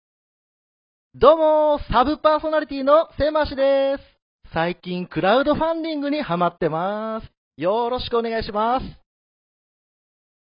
そのためか日常会話で関西弁と標準語のコントロールが可能。